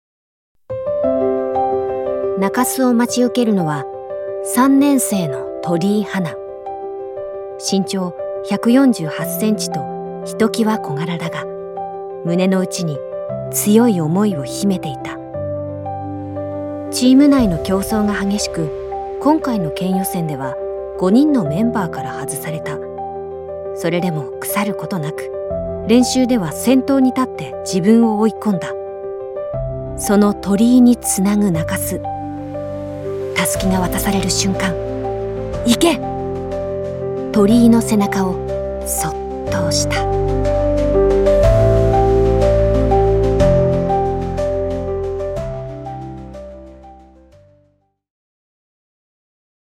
BGMあり